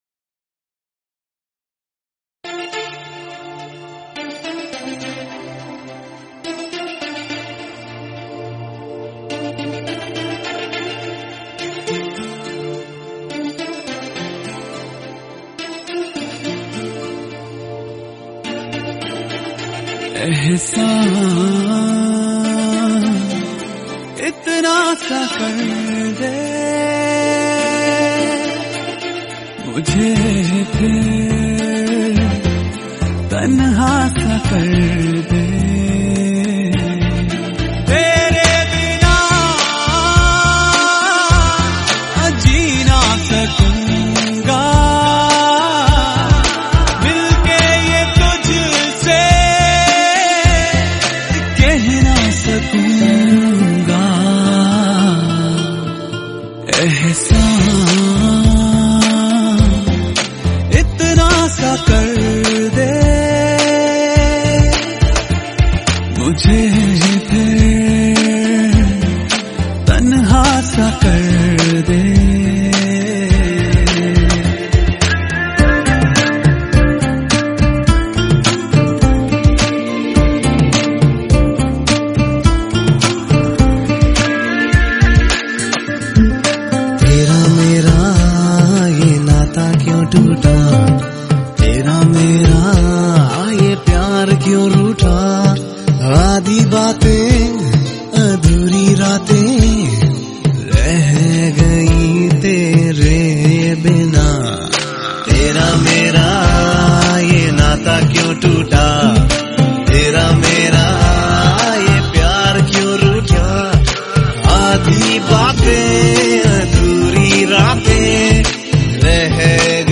hindi song
sweet voice